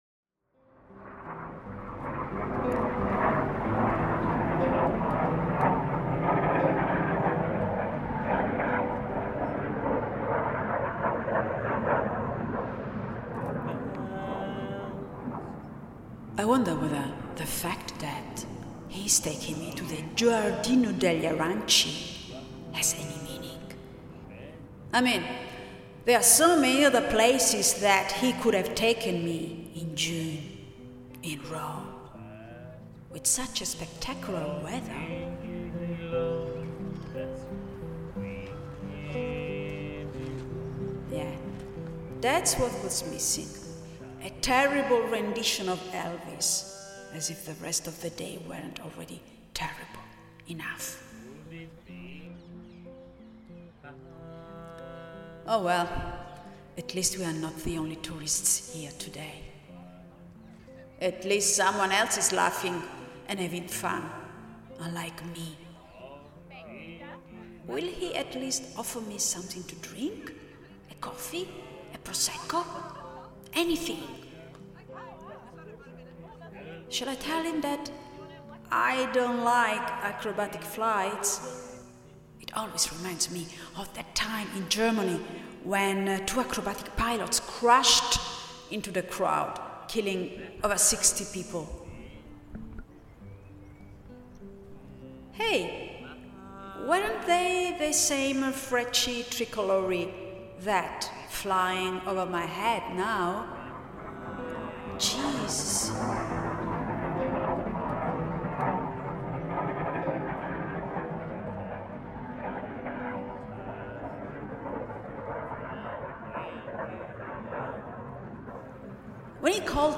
in which multiple artists create a new composition using the same original field recording as source material and inspiration